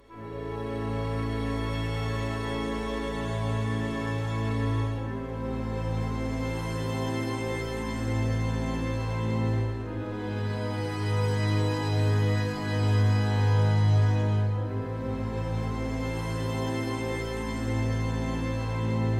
暗弦和弦
描述：只是一个黑暗的字符串和弦...
Tag: 100 bpm Cinematic Loops Strings Loops 3.23 MB wav Key : Unknown FL Studio